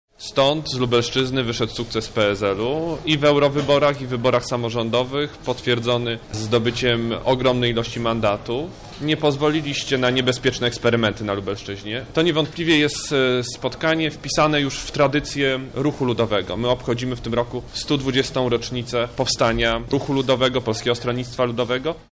Kilka tysięcy osób wzięło udział w opłatku ludowym w hali MOSiR.
Podczas spotkania liderzy partii podkreślali swój sukces podczas wyborów samorządowych. Ten opłatek jest największym w Polsce – podkreśla Władysław Kosiniak-Kamysz, wiceprezes PSL